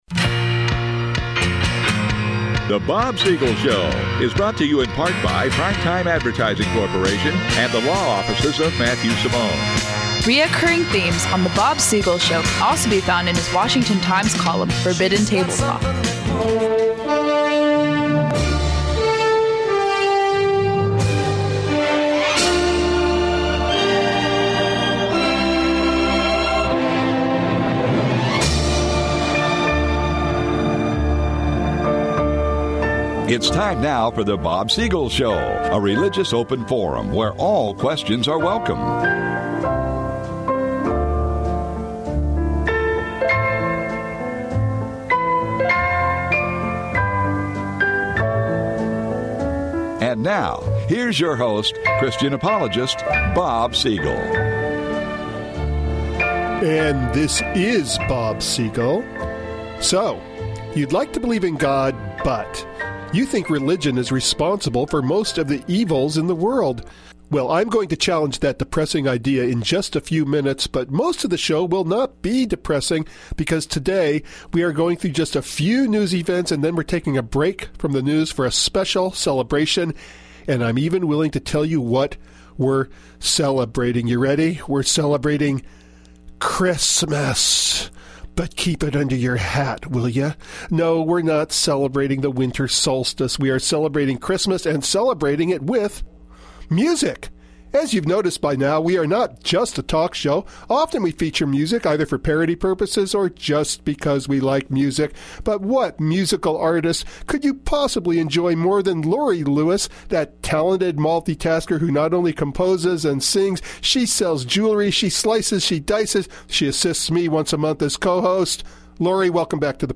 In a repeat broadcast of a favorite radio show which first aired December of 2007
piano